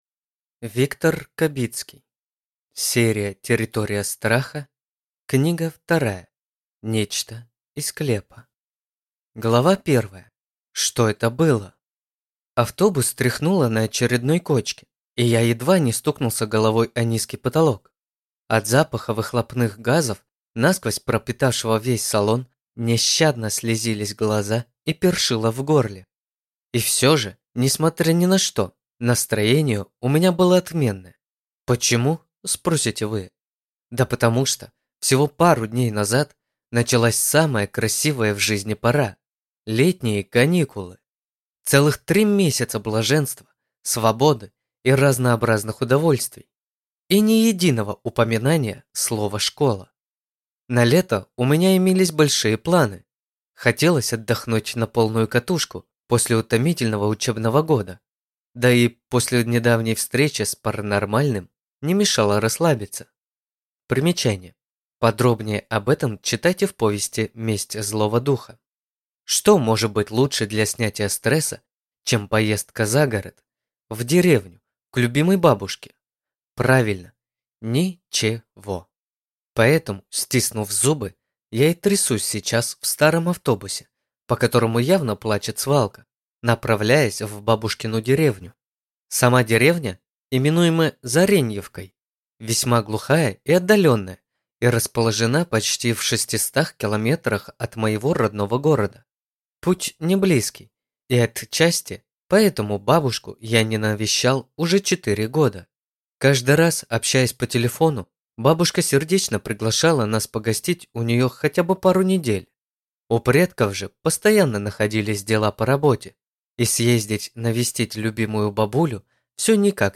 Аудиокнига Нечто из склепа | Библиотека аудиокниг
Прослушать и бесплатно скачать фрагмент аудиокниги